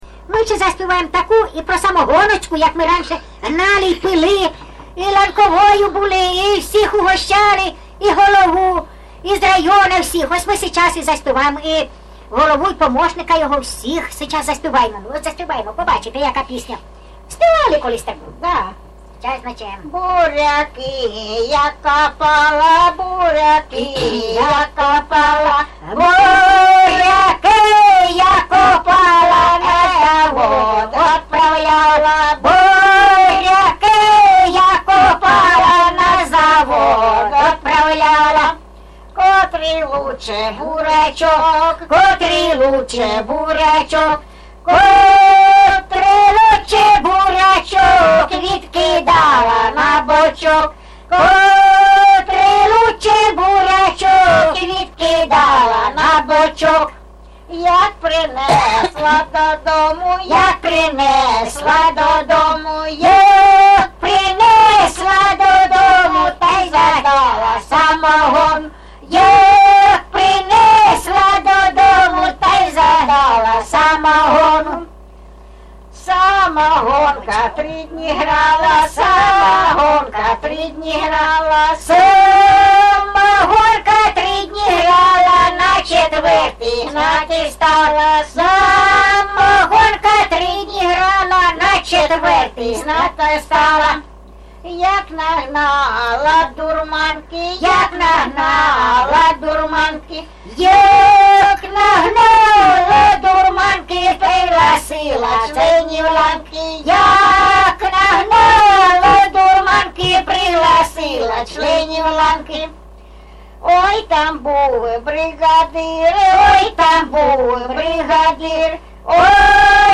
ЖанрЖартівливі
Місце записус. Закітне, Краснолиманський (Краматорський) район, Донецька обл., Україна, Слобожанщина